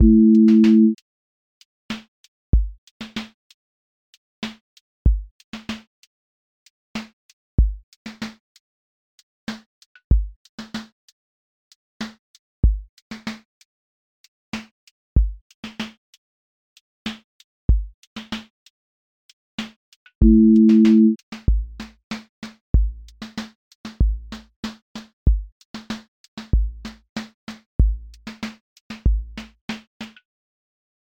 QA Probe neo-soul 30s
• voice_kick_808
• voice_snare_boom_bap
• voice_hat_rimshot
• tone_warm_body
• motion_drift_slow